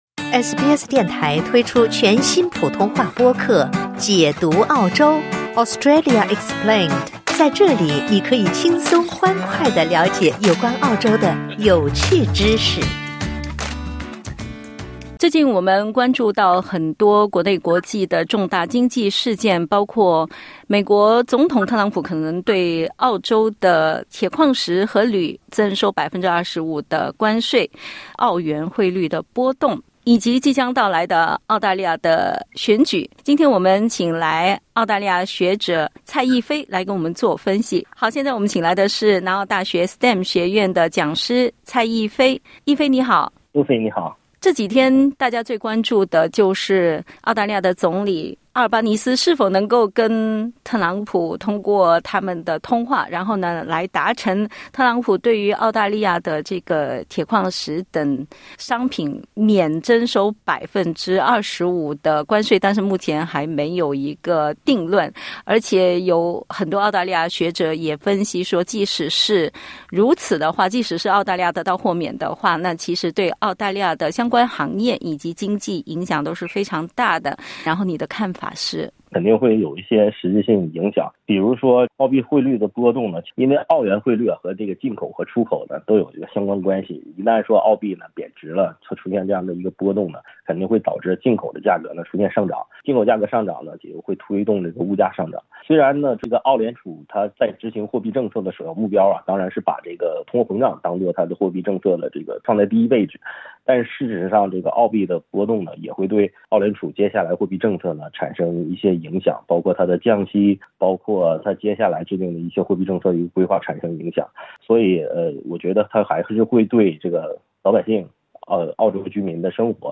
（点击音频收听详细采访） 近日，美国总统唐纳德·特朗普表示，他计划对美国进口的所有钢铁和铝征收25%的关税。